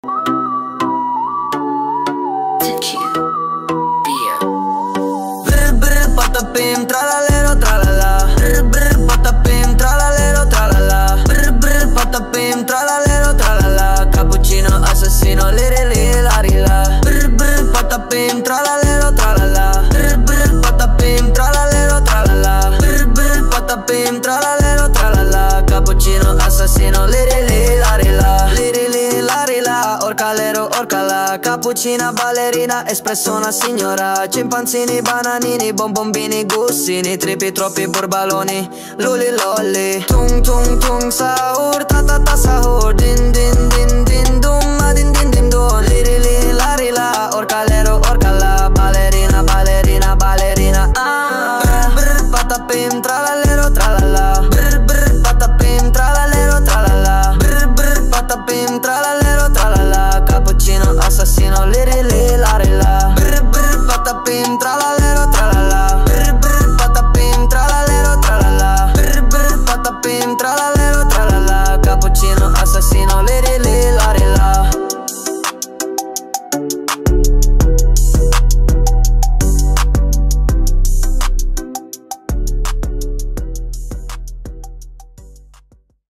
Brainrot_rap.mp3